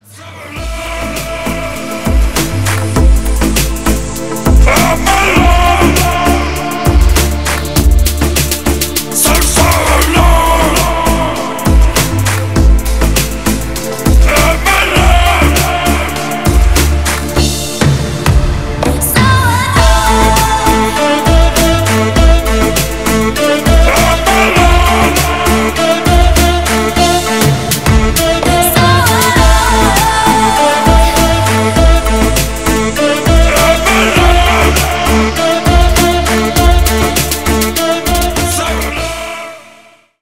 • Качество: 320, Stereo
мужской голос
женский голос
Electronic
дуэт
Стиль: deep house